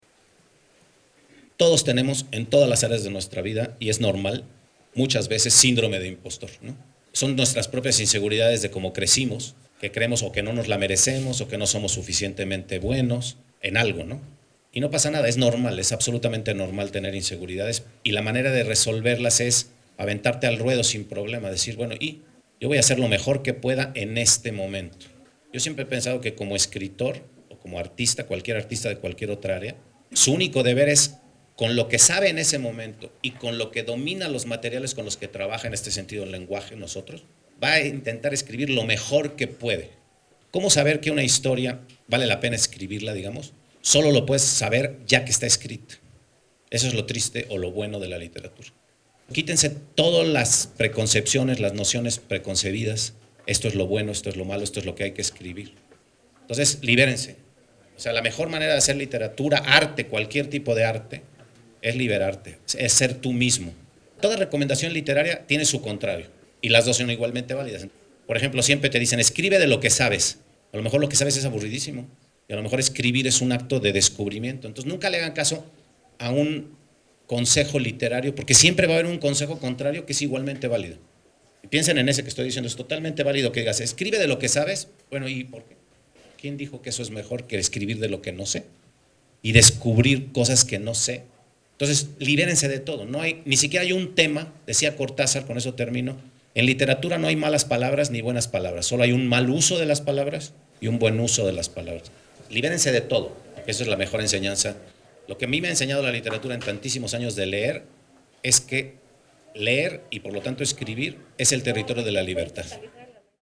El escritor poblano Pedro Ángel Palou García charló con estudiantes de la Preparatoria Benito Juárez García de la BUAP, a quienes compartió sus recuerdos como alumno de la Licenciatura de Lingüística y Literatura Hispánica de la institución, su incursión en la escritura, sus historias pendientes por contar y su trayectoria por diversos géneros literarios, desde la poesía hasta la novela histórica.
Dr. Pedro Ángel Palou, escritor.mp3